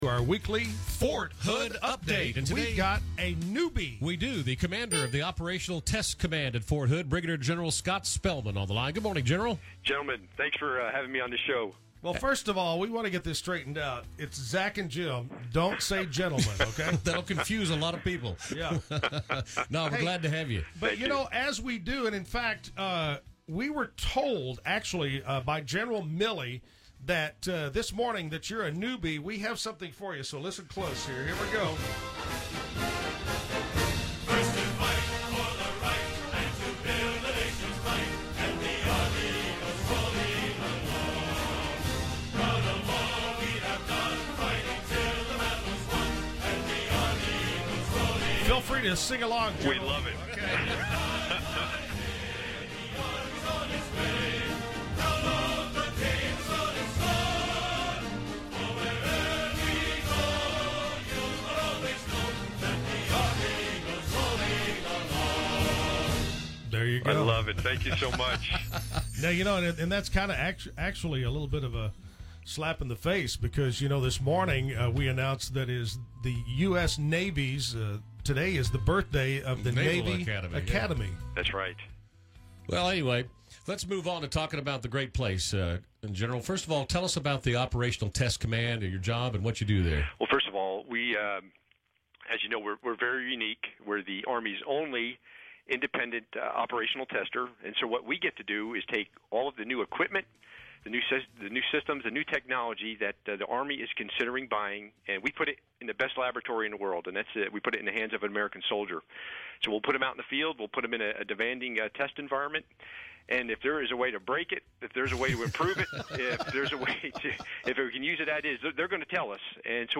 Radio interview with Brig. Gen. Scott Spellmon